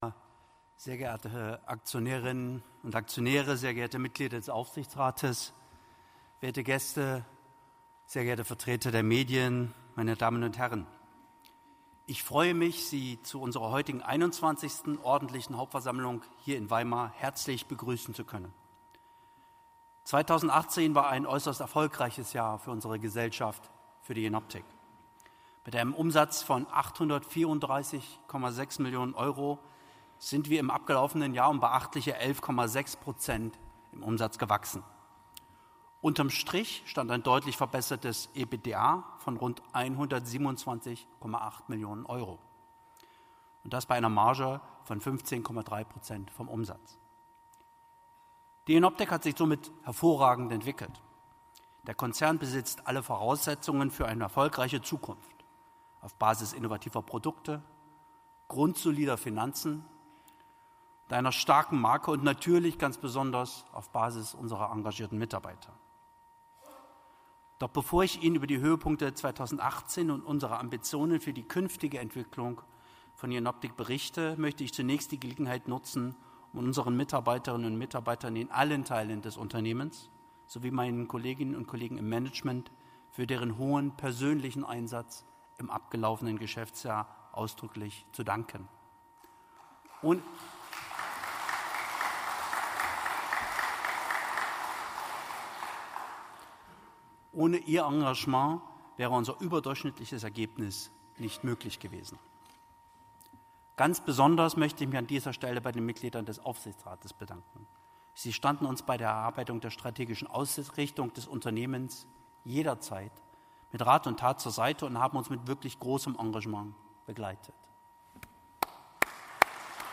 Ordentliche Hauptversammlung
redemittschnitt.mp3